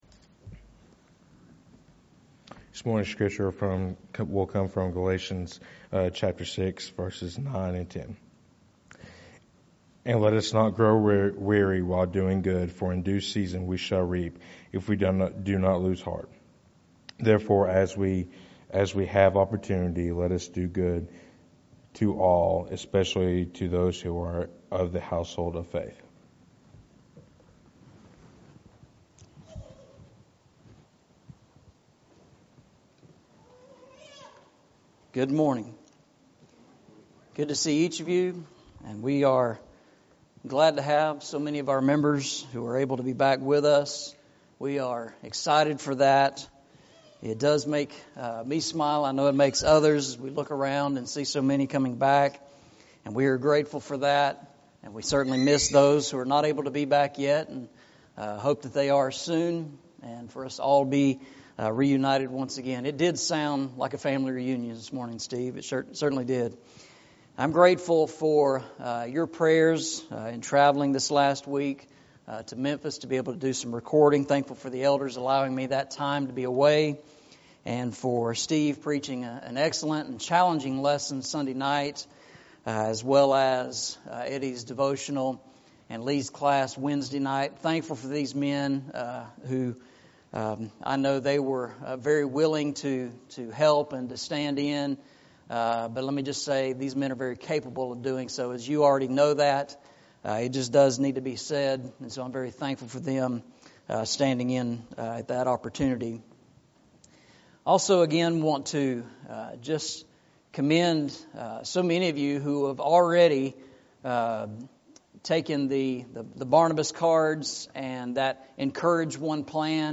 Eastside Sermons
Galatians 6:9-10 Service Type: Sunday Morning « The Challenge to Excel The Pattern of the Church